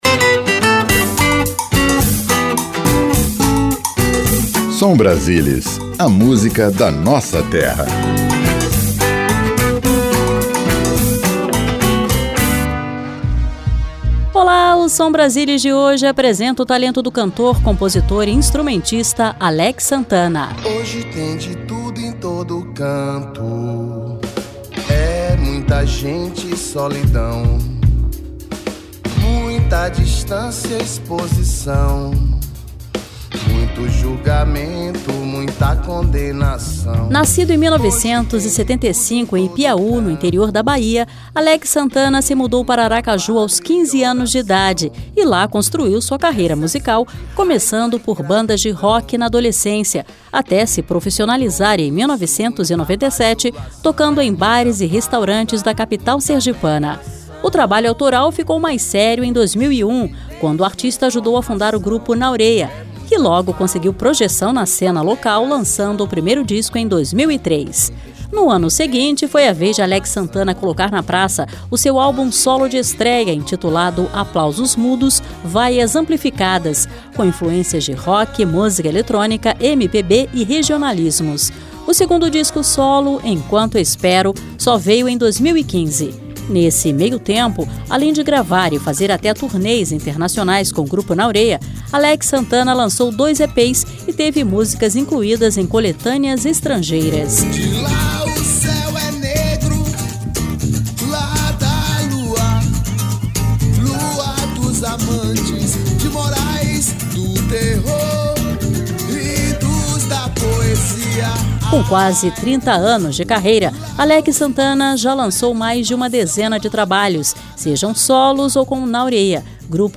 Seleção Musical: